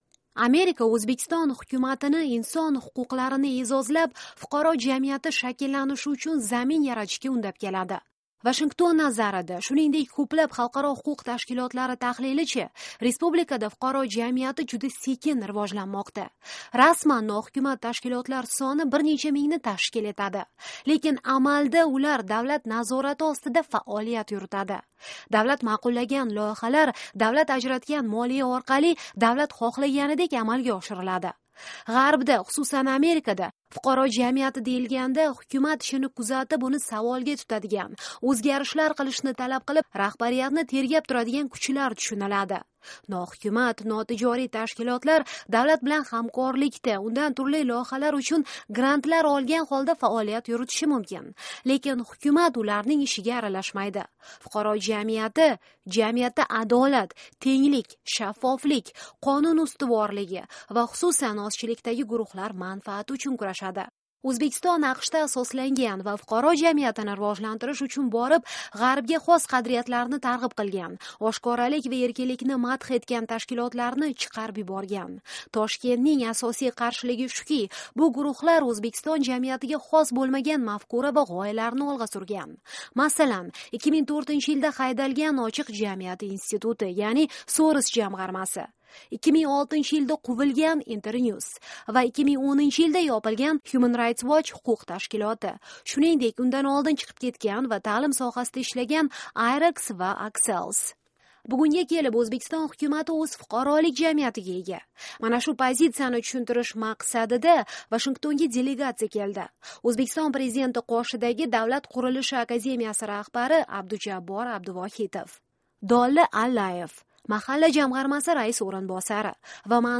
Embed share Mahalla xususida davra suhbati - O'zbekiston delegatsiyasi Vashingtonda